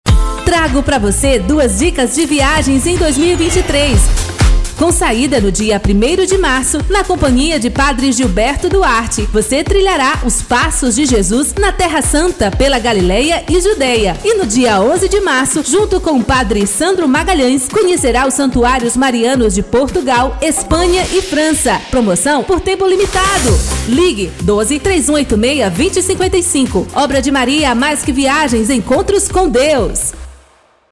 SPOT-PEREGRINACAO-TERRA-SANTA-PROMOCIONAL-E-SANTUARIOS-MARIANOS-0030.mp3